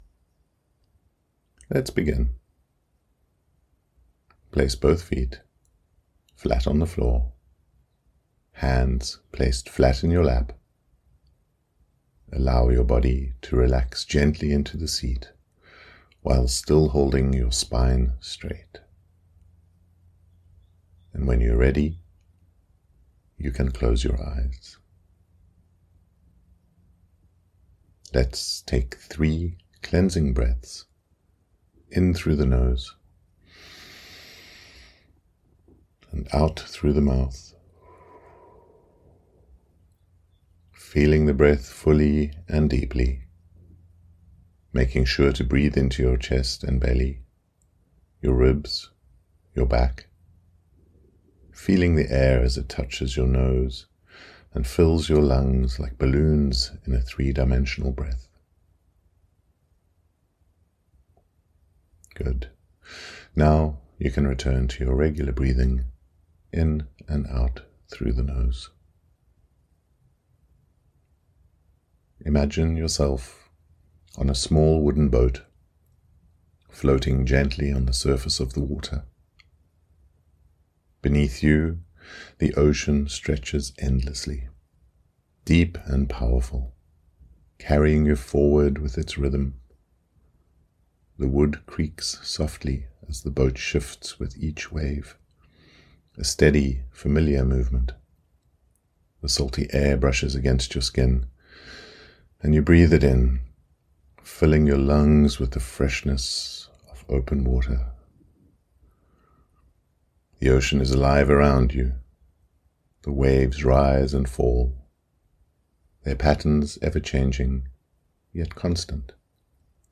Sailing Stormy Seas Meditation